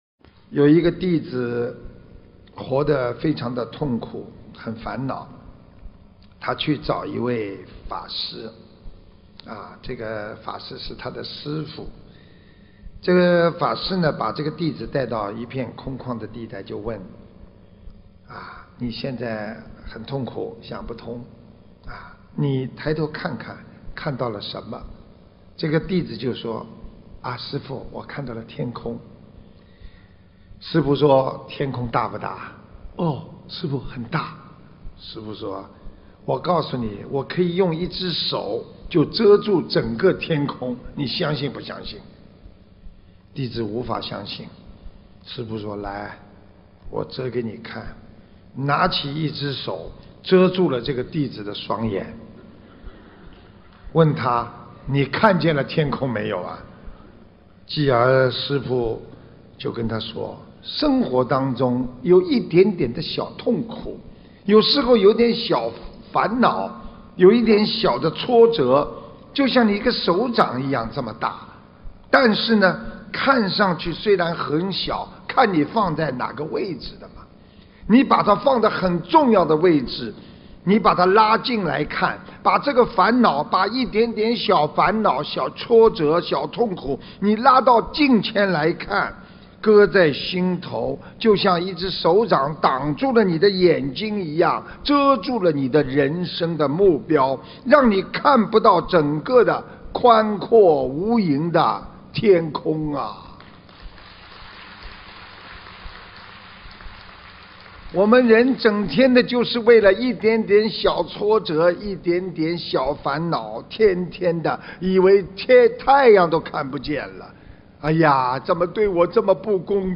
音频：用一只手掌遮住了整个天空·师父讲小故事大道理